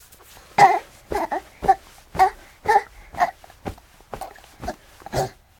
bounce.ogg